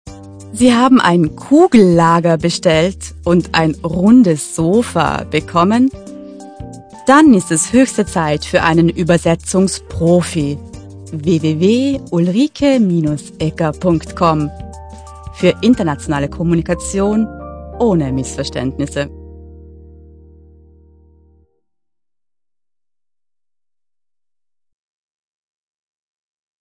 • AUDIOPROBE: Werbespot
durch eine gelungene Kombination von Text und Stimme kommt die Botschaft an
werbespot.mp3